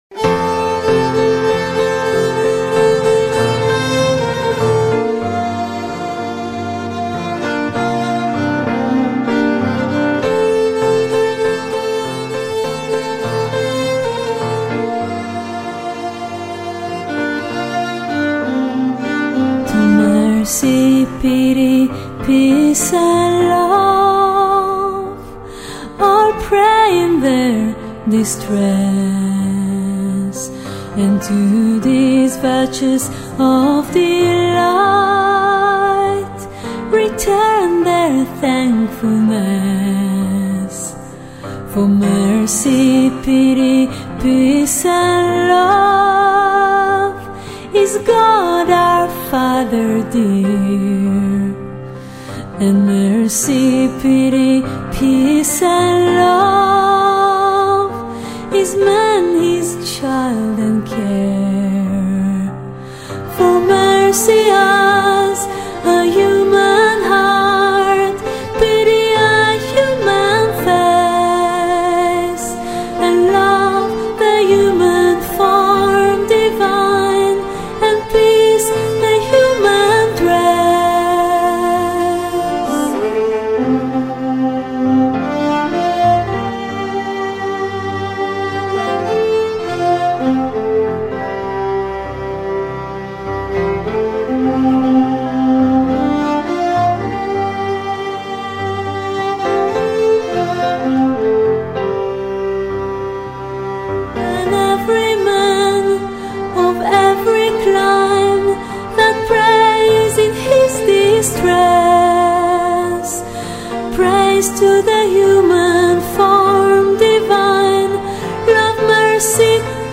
Violin, Piano